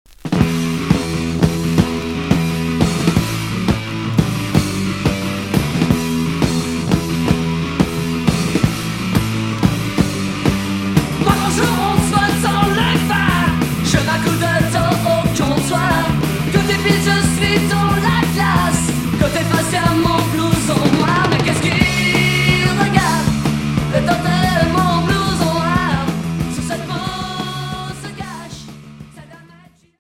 Rock Hard